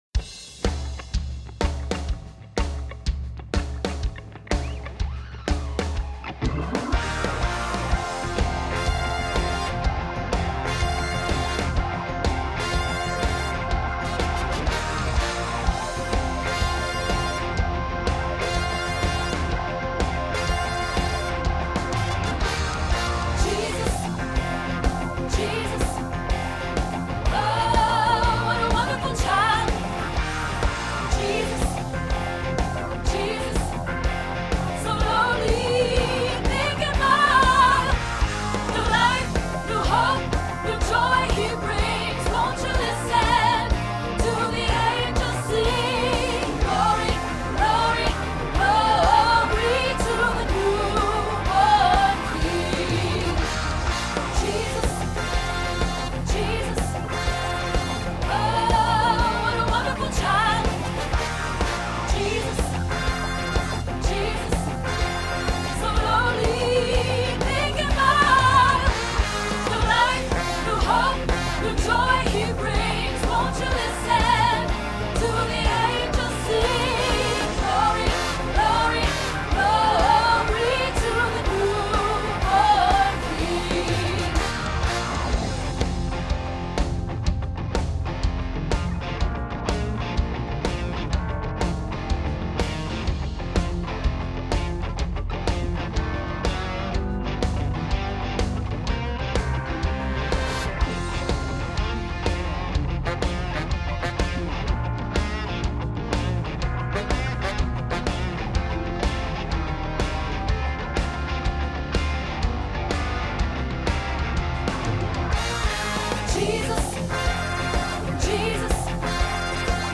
There are full tracks to listen to, and individual tracks for each voice part.
08-Jesus-What-a-wonderful-child-Bass-2.wma